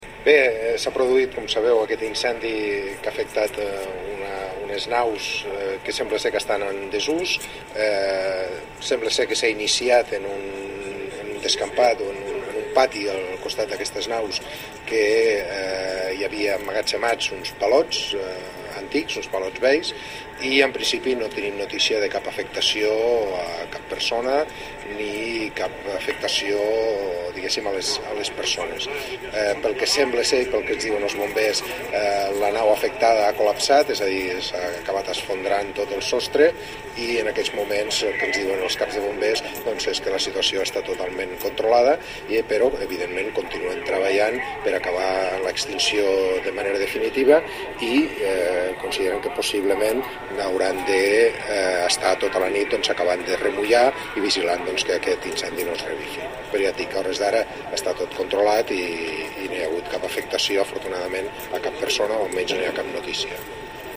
Els tinents d’alcalde Rafael Peris i Joan Gómez s’han personat en el lloc de l’incendi, on els efectius de la Guàrdia Urbana han instal·lat un perímetre de seguretat, i han explicat com s’han produït els fets. Tall de veu R. Peris.